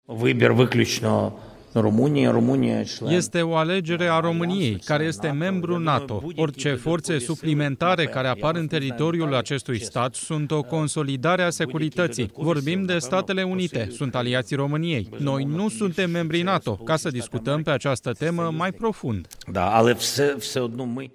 Într-o conferință comună, alături de președintele Ucrainei, Nicușor Dan a declarat că scopul noilor forțe americane este garantarea securității.
12mar-20-Zely-consolidare-TRADUS.mp3